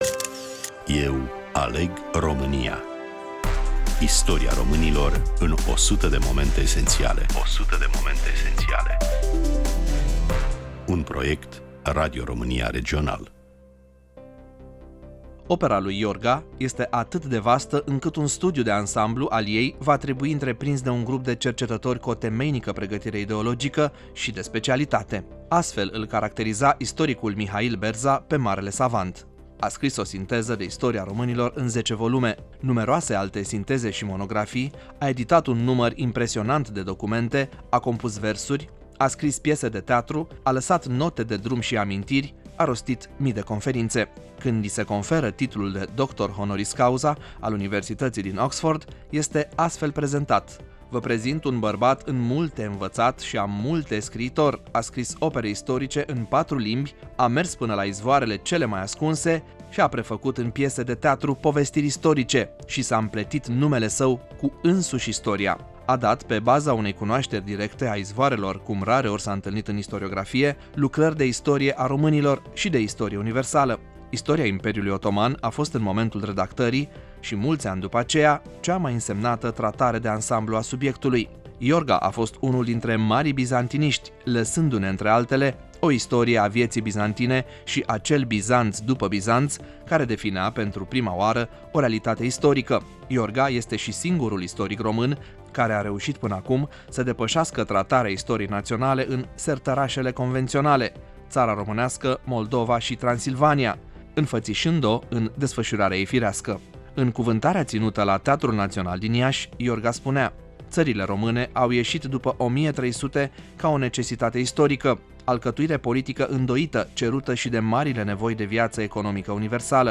Prezentare, voice over